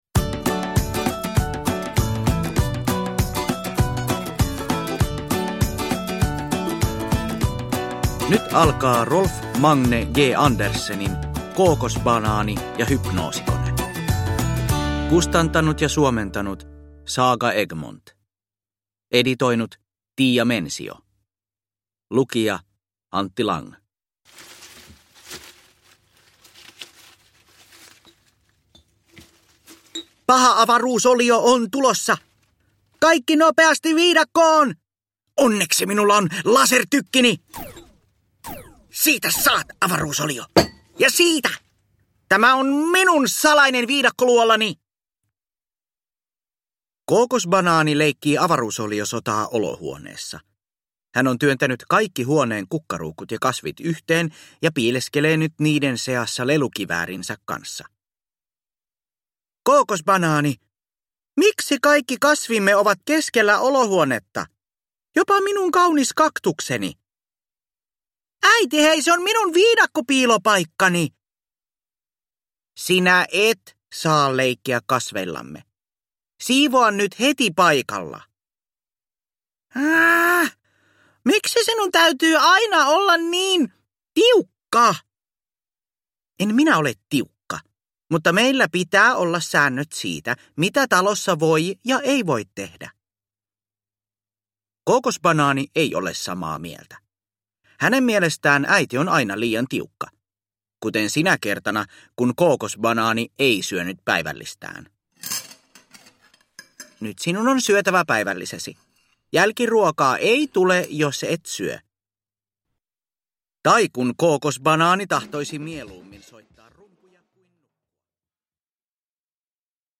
Kookosbanaani ja hypnoosikone – Ljudbok